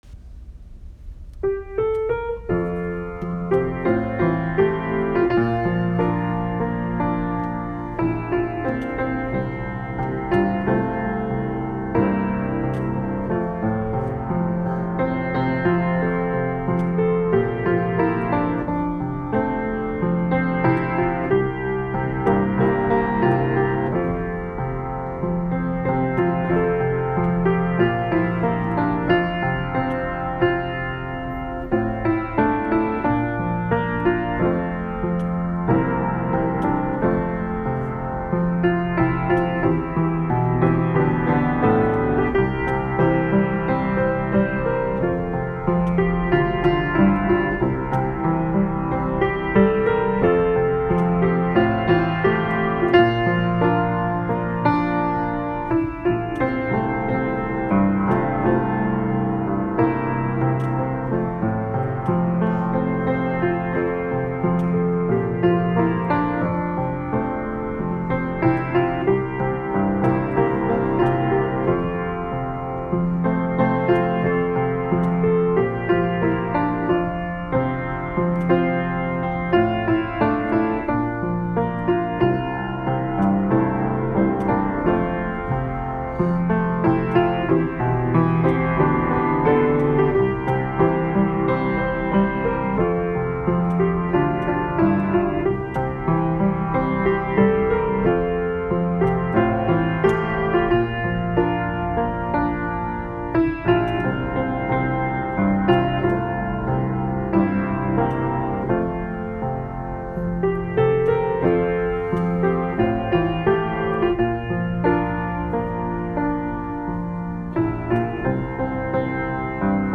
HNC-163-Direção-Divina-Playback.m4a